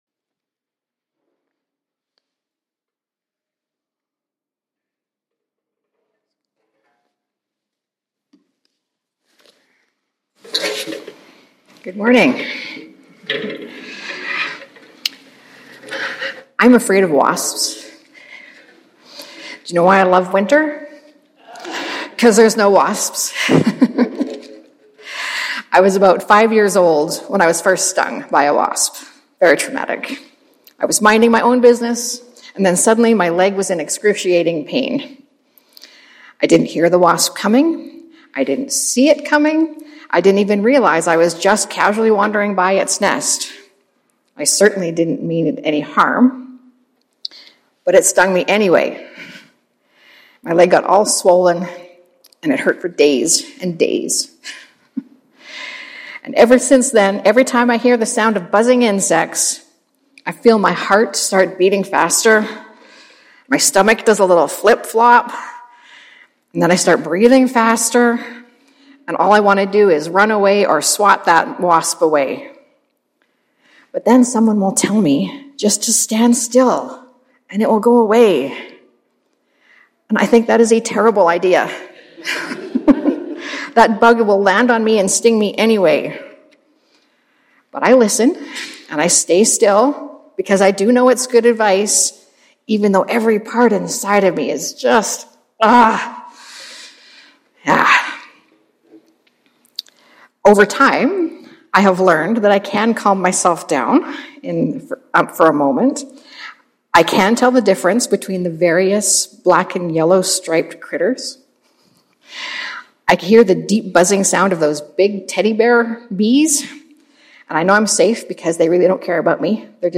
Sermon Podcast for First Baptist Church of Edmonton, Alberta, Canada